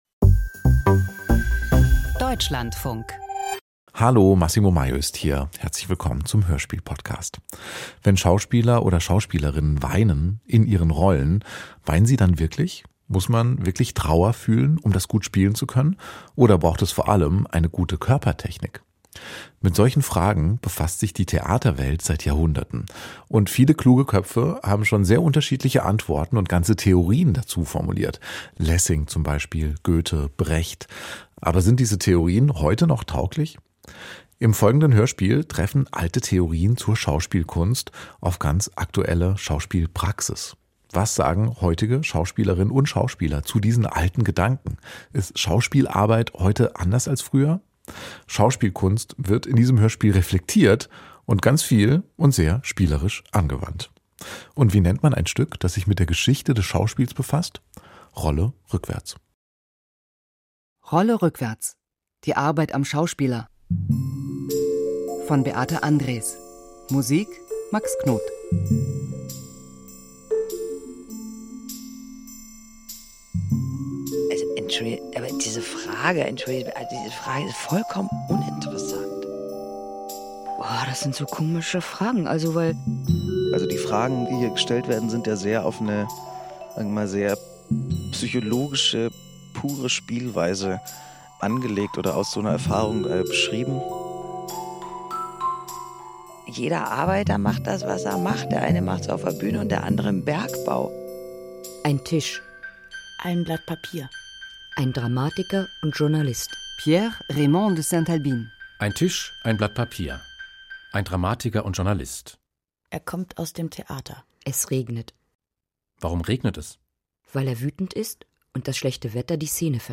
Hörspiel
Klassiker der Literatur, spannende Dramen, unterhaltende Erzählungen und innovative Sound Art. Hier gibt es große Stories, starke Stimmen und feine Klänge – Hörspiele von Deutschlandfunk und Deutschlandfunk Kultur.